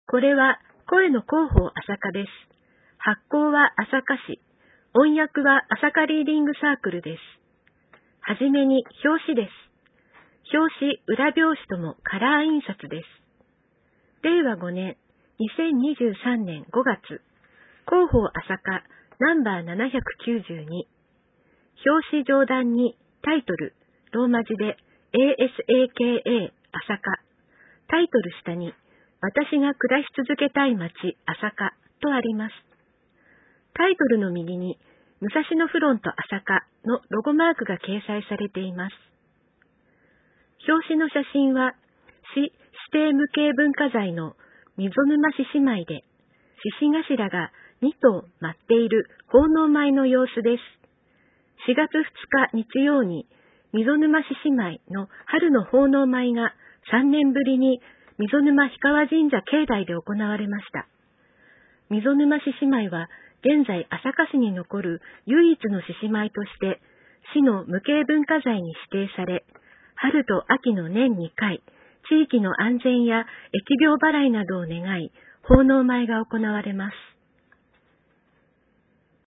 ｢声の広報あさか」は、市内のボランティア「朝霞リーディングサークル」のご協力で、視覚に障害がある方のご自宅にＣＤ（デイジー形式）を郵送しています。